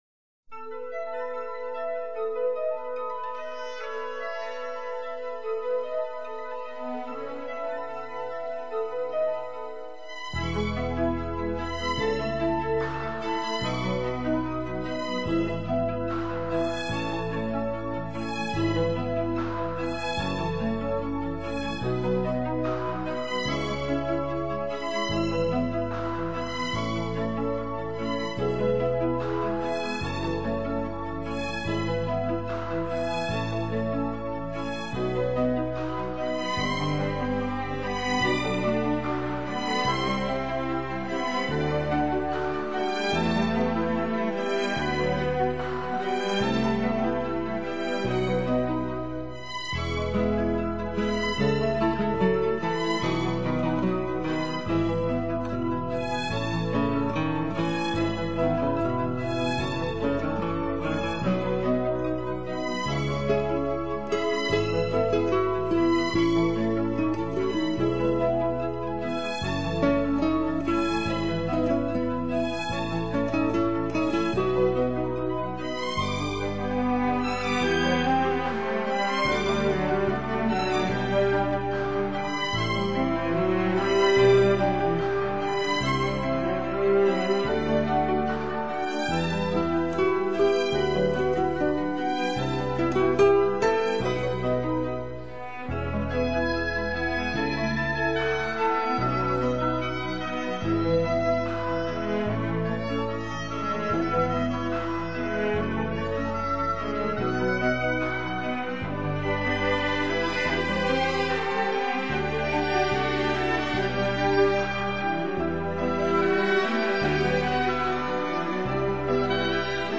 音乐类型：跨界融合 fusion
音乐风格：Neo Classical，室内乐
这就是古典元素（Oboe, Flauto, Corno, Archi, Pianoforte）
和现代元素（Batteria, Chitarra Basso, Synth）如何在作品当中相互融合的过